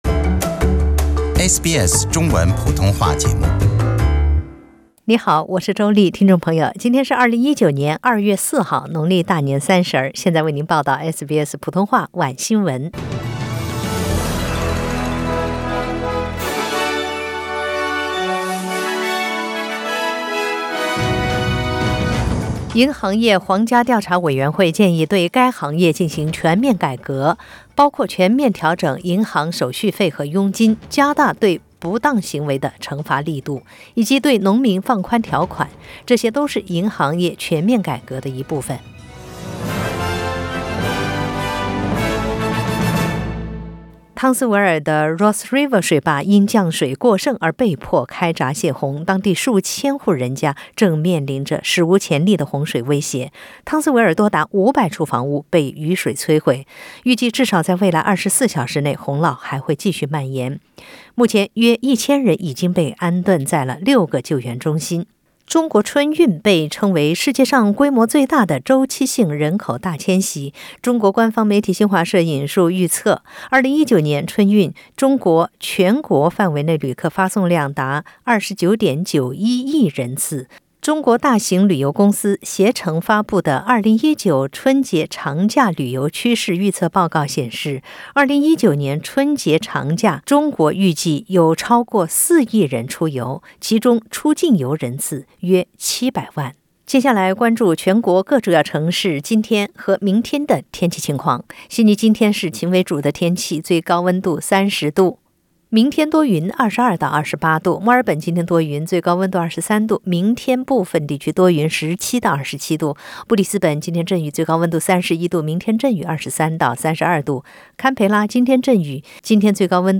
SBS 晚新闻 （2月4日）